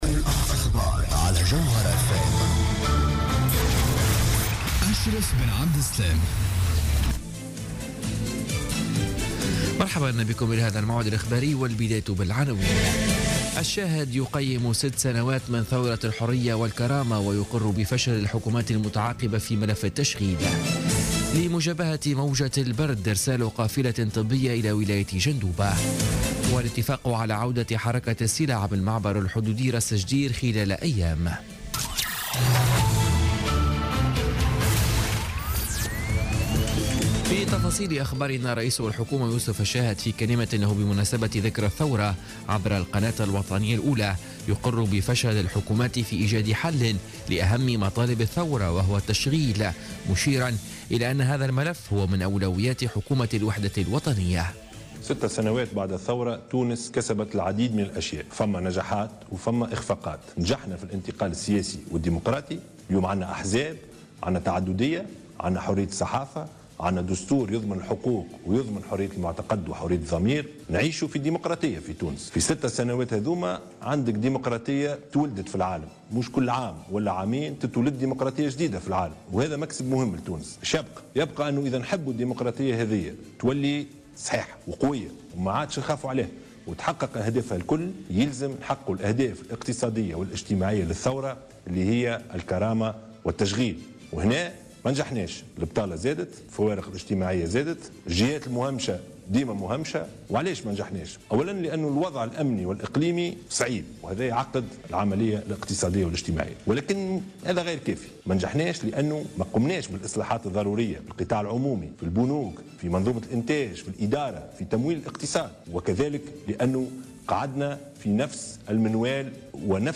نشرة أخبار منتصف الليل ليوم السبت 14 جانفي 2017